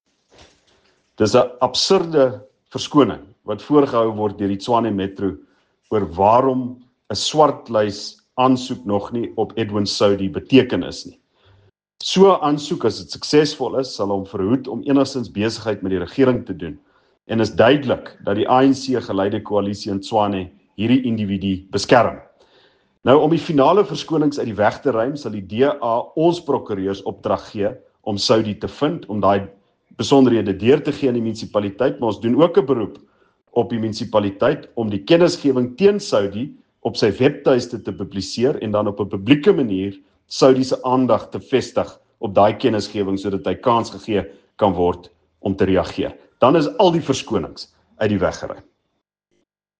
Afrikaans soundbites by Ald Cilliers Brink